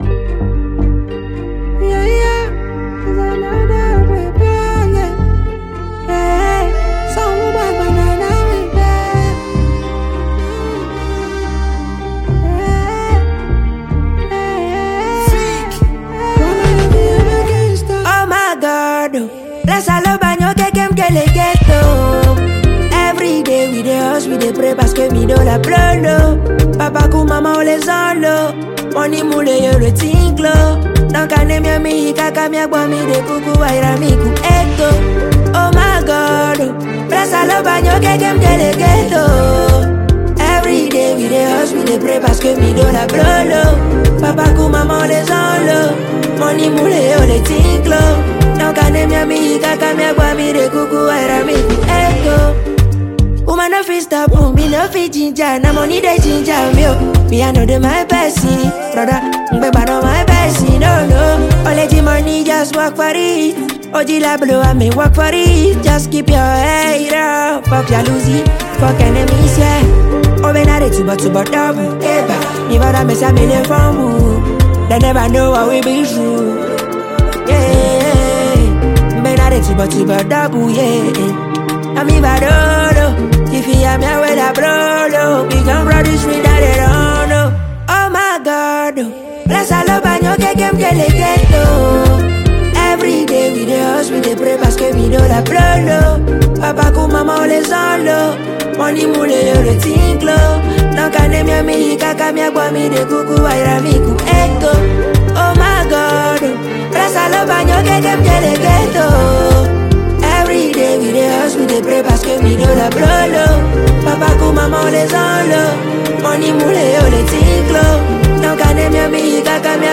Genre: Afrobeat / Conscious Music